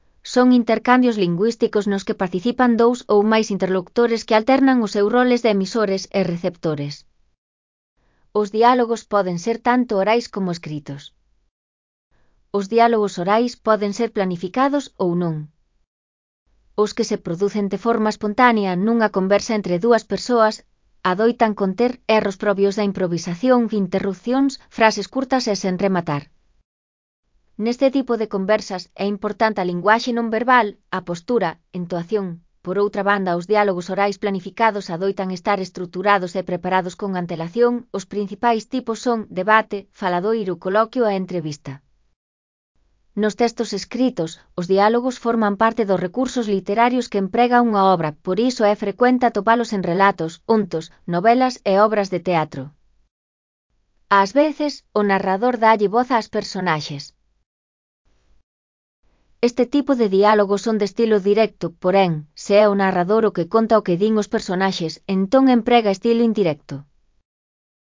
Elaboración propia (Proxecto cREAgal) con apoio de IA, voz sintética xerada co modelo Celtia. . Características dos textos dialogados. (CC BY-NC-SA)